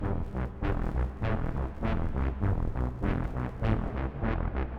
Em (E Minor - 9A) Free sound effects and audio clips
• Brass Texture Delayed.wav
Brass_Texture_Delayed__K6C.wav